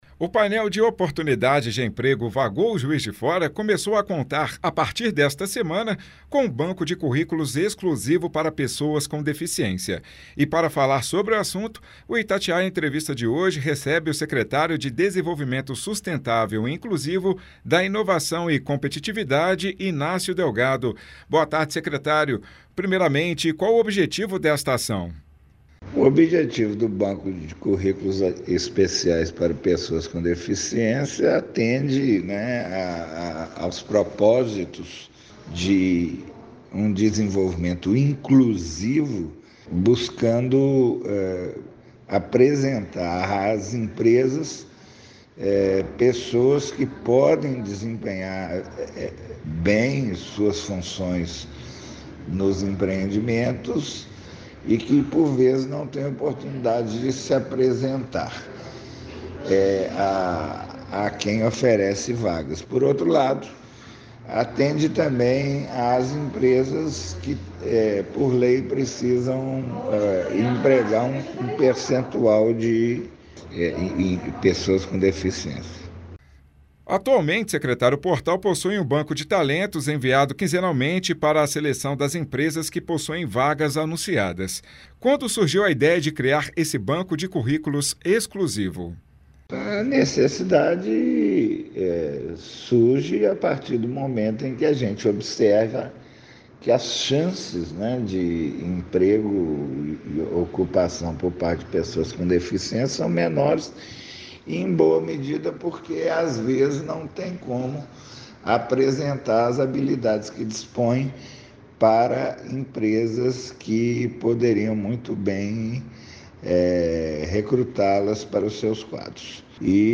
O painel de oportunidades de emprego “Vagou, Juiz de Fora” começou a contar, a partir desta semana, com um banco de currículos exclusivo para pessoas com deficiência. Para falar sobre o assunto, o Itatiaia Entrevista recebe o secretário de Desenvolvimento Sustentável e Inclusivo, da Inovação e Competitividade, Ignácio Delgado.
ENTREVISTA.mp3